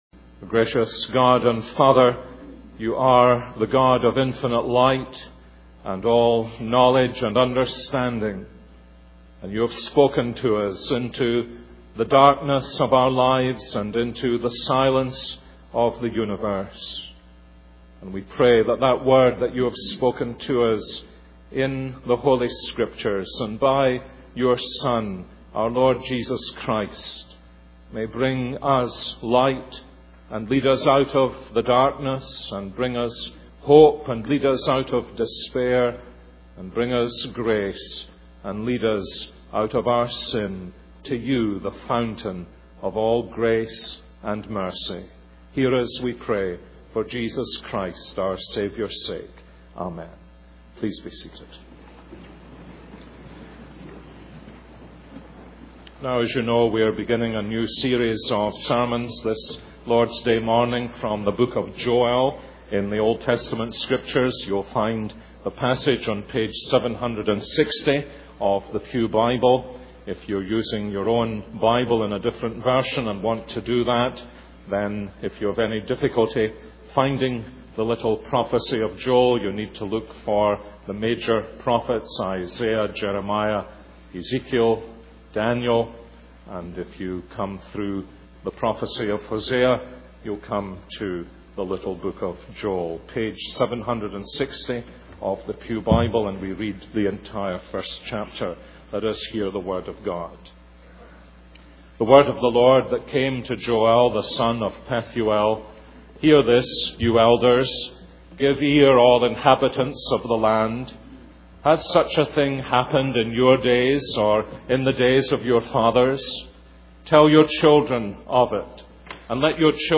This is a sermon on Joel 1:1-20.